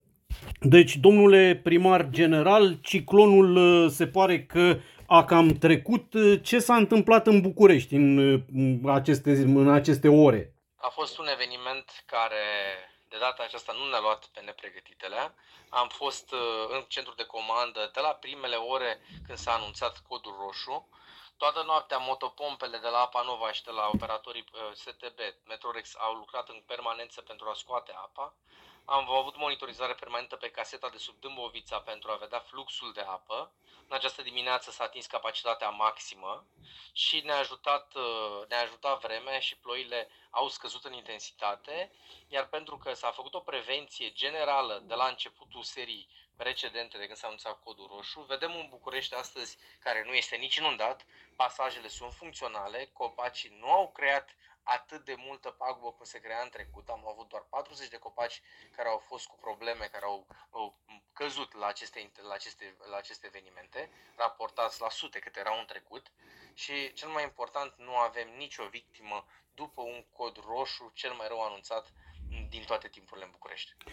În cadrul Comitetului pentru Situații de Urgență al Municipiului București,  Primăria Capitalei va recomanda reluarea, începând de mâine,  a cursurilor, atât în învățământul preuniversitar,  cât și în universități,  a declarat, pentru București FM,  primarul general al Capitalei,  Stelian Bujduveanu.